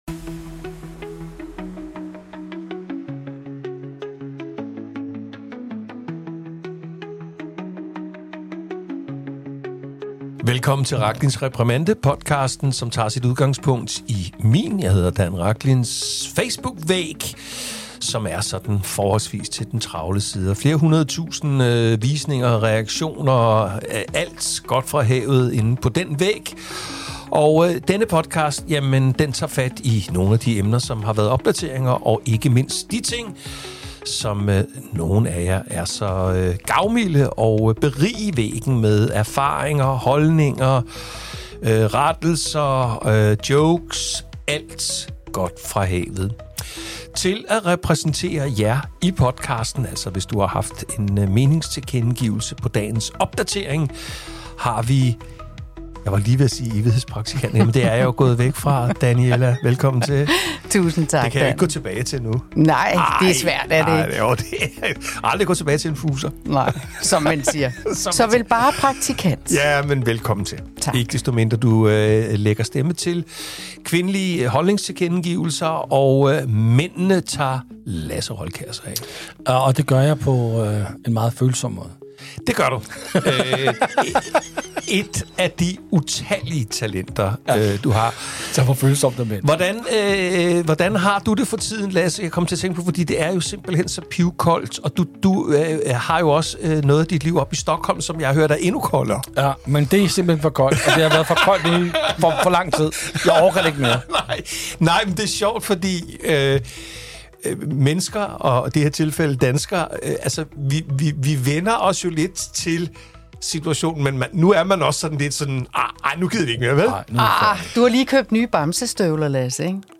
Medværter og stemmer til kommentarsporet